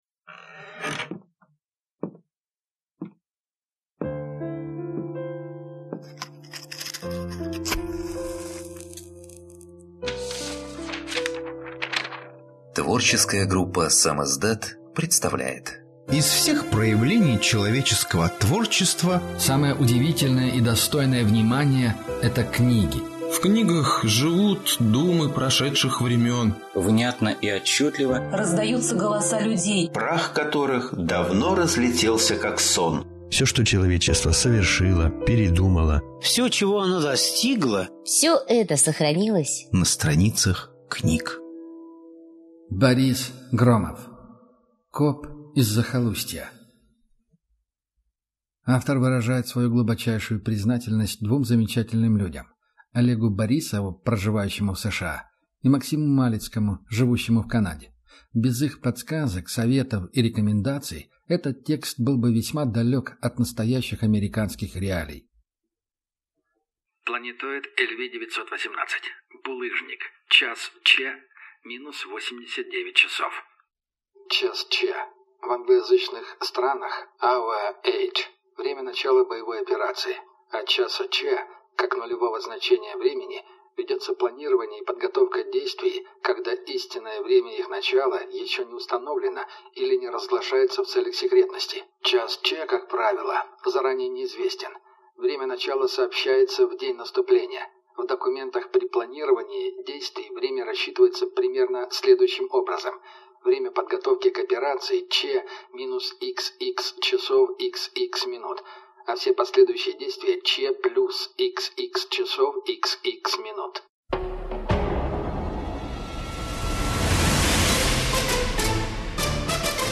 Аудиокнига Коп из захолустья | Библиотека аудиокниг